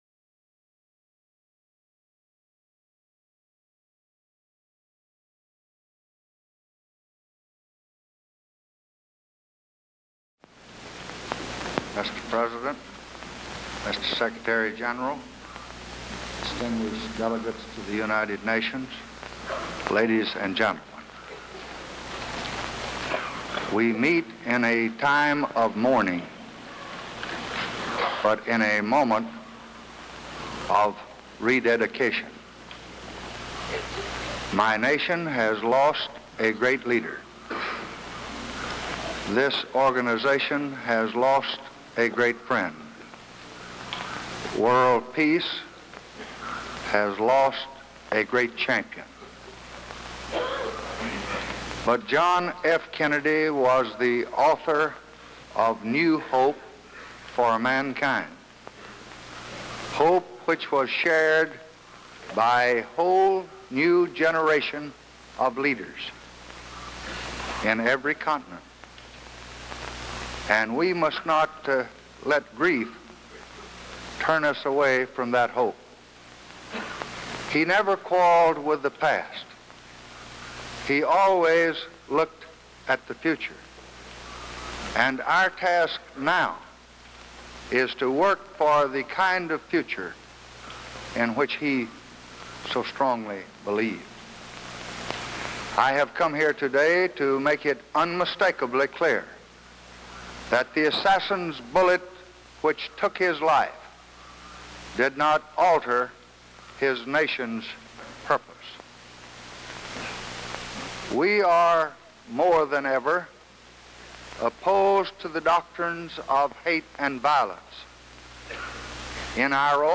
December 17, 1963: Address to the U.N. General Assembly
Presidential Speeches | Lyndon B. Johnson Presidency